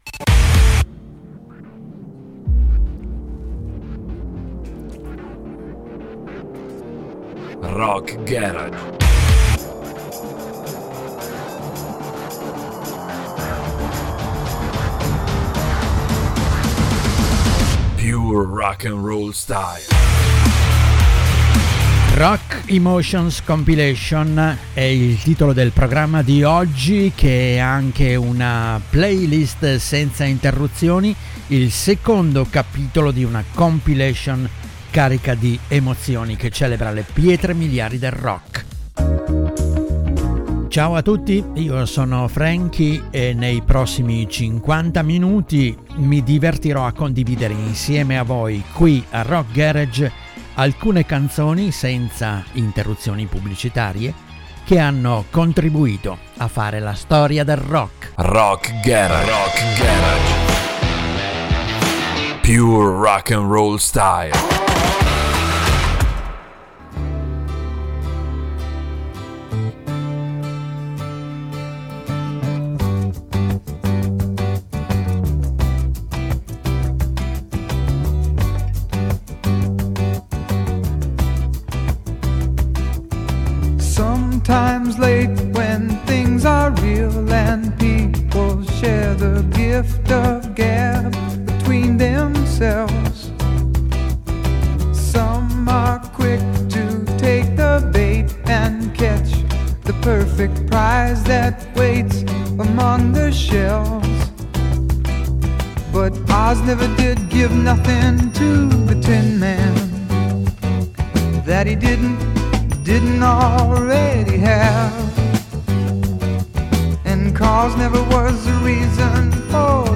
playlist senza interruzioni carica di emozioni